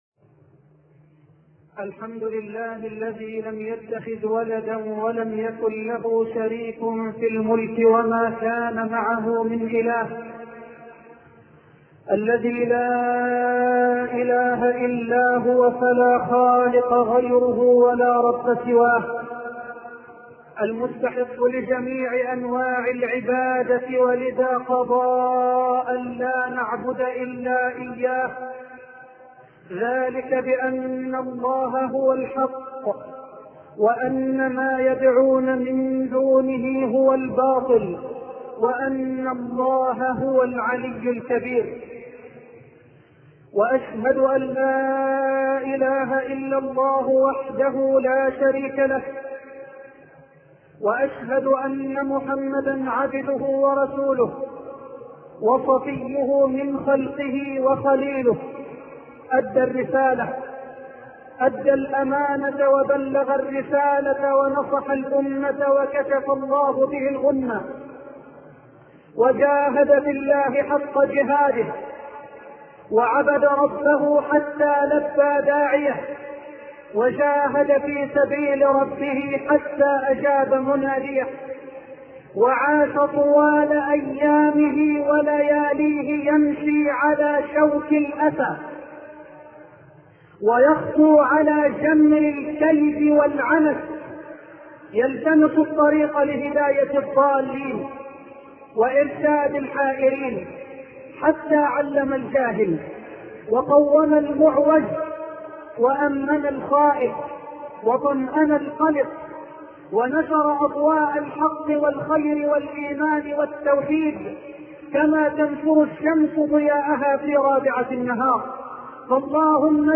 شبكة المعرفة الإسلامية | الدروس | مع أعظم داعية [1] |محمد حسان مع أعظم داعية [1] محمد حسان  الاشتراك  لدي مشكلة  دخول 7/8/1438 مع أعظم داعية [1] مع أعظم داعية [1] Loading the player...